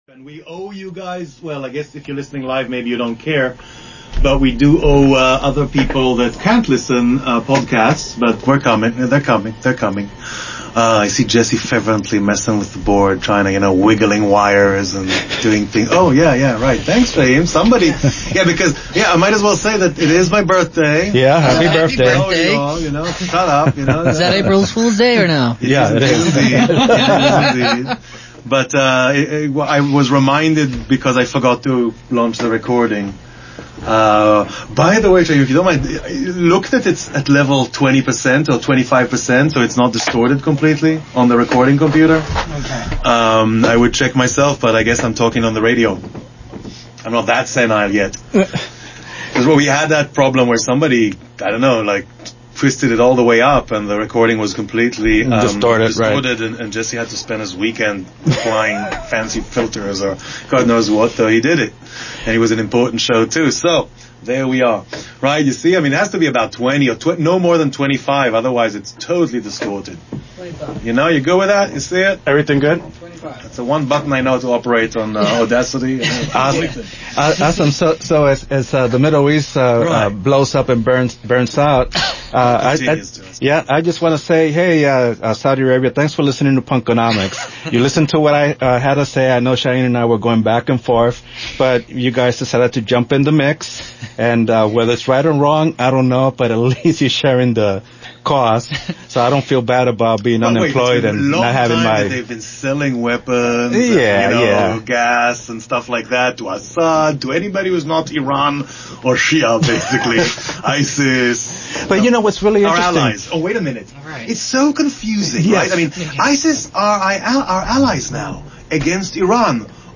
and me talking about lots of things and experimenting with incorporating bits of Professor Richard Wolff’s excellent podcast Economic Update (on truthout)